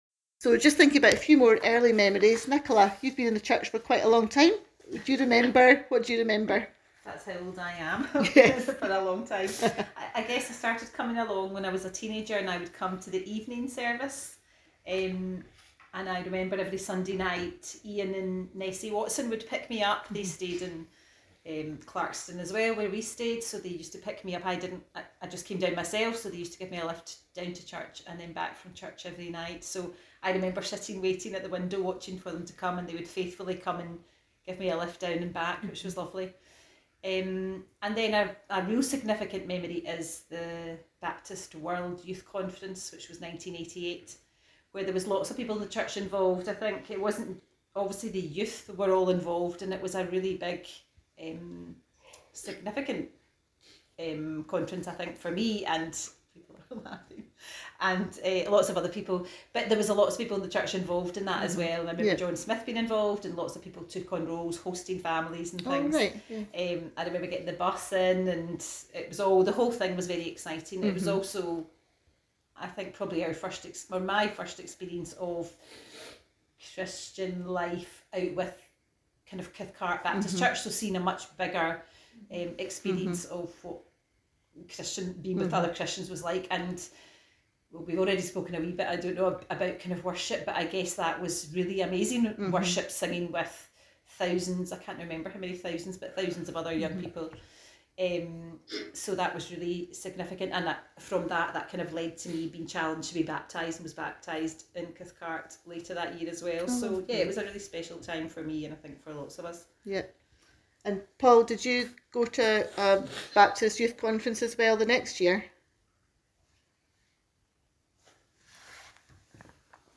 Stories from a group of friends who were growing up in the 1980s and 1990s in the church, recorded 2023.